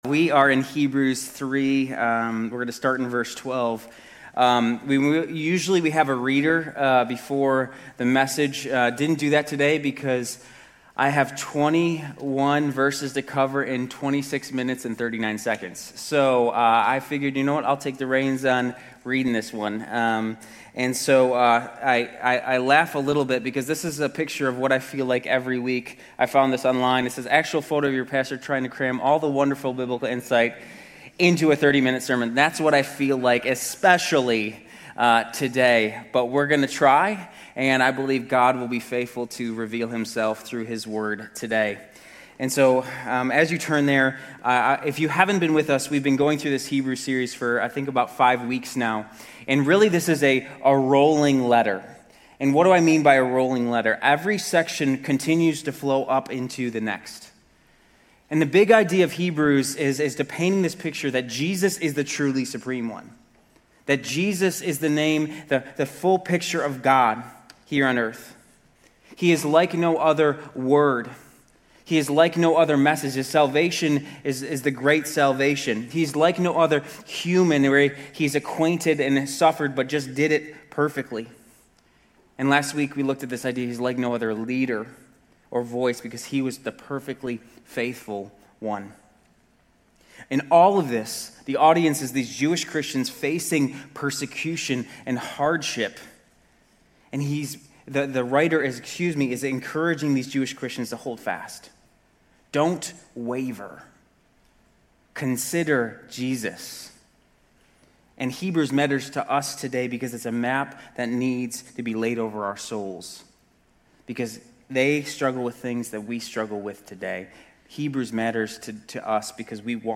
Grace Community Church University Blvd Campus Sermons 10_12 University Blvd Campus Oct 13 2025 | 00:36:10 1x 00:00 / 00:36:10 Subscribe Share RSS Feed Share Link Embed